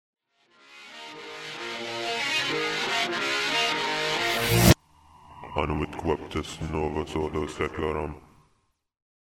hard rock
Tipo di backmasking Rovesciato